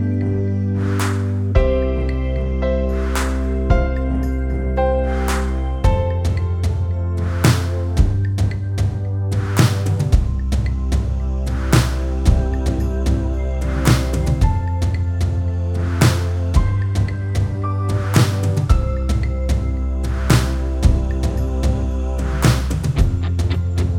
no Backing Vocals Christmas 3:41 Buy £1.50